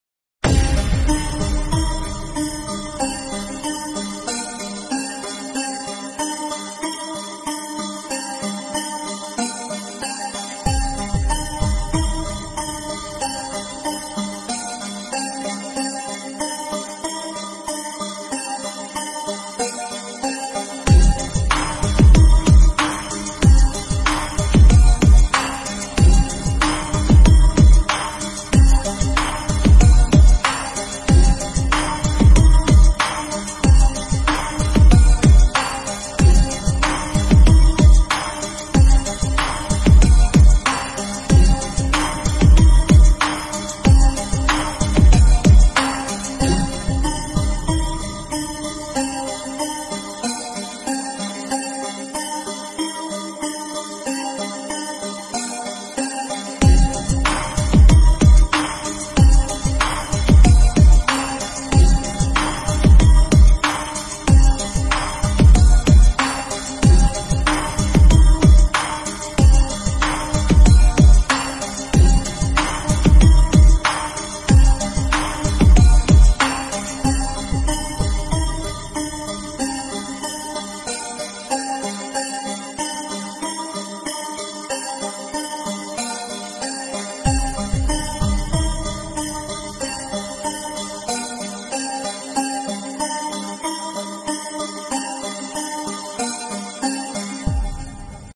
New 8k Attitude Song Bass Boosted